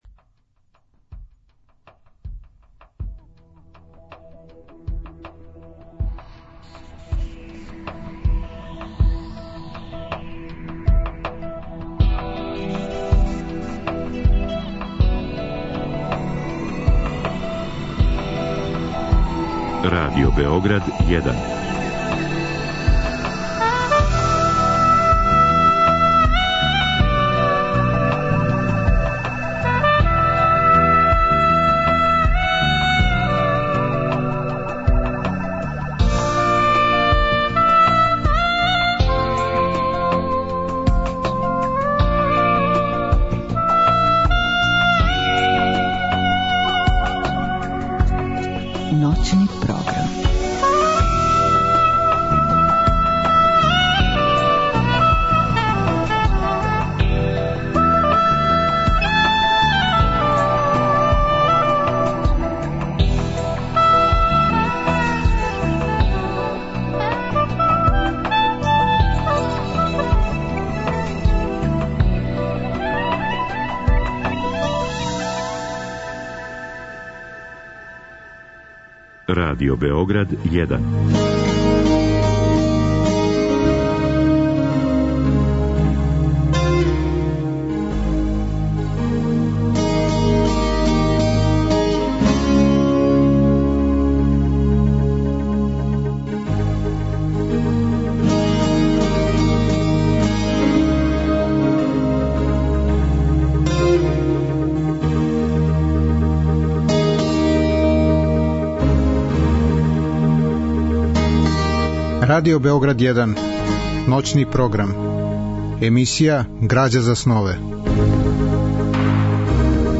Разговор и добра музика требало би да кроз ову емисију и сами постану грађа за снове.